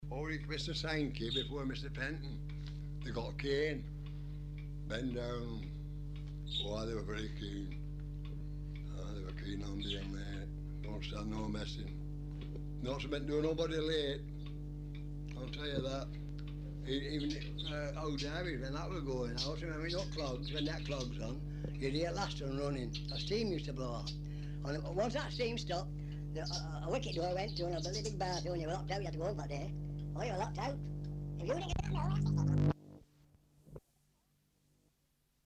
It speeds up, with their voices sounding more high pitch
Example of batteries dying during an oral history interview.
So this means that the original signal goes over the playback head and sounds much faster to our ears as it was recorded on a shorter portion of the tape, which creates the effect of it speeding up rather than slowing down.
batteries-dying.mp3